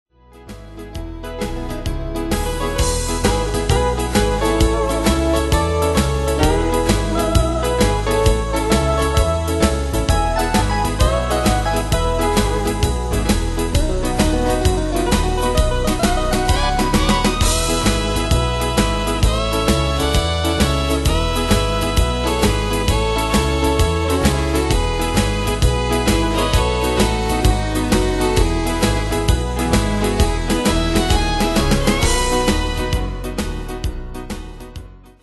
Style: Country Année/Year: 1995 Tempo: 131 Durée/Time: 3.21
Pro Backing Tracks